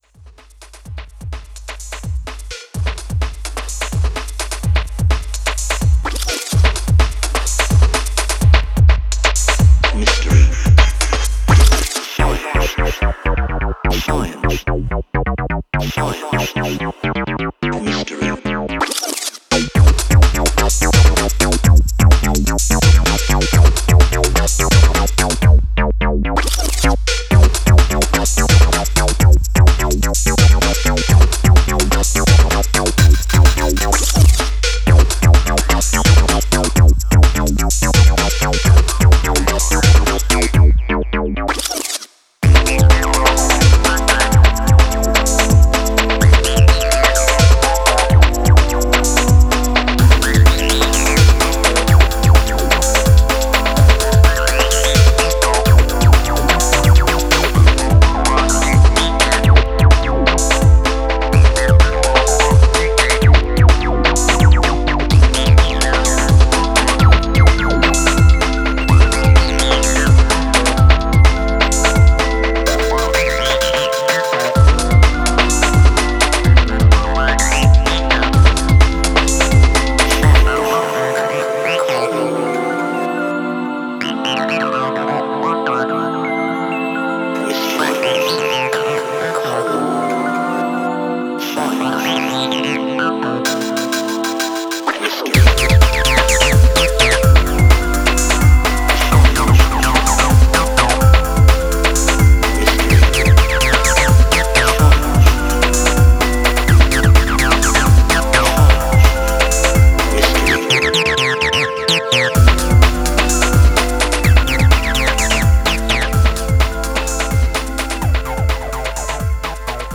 5 more banging toons for the dance floor
Techno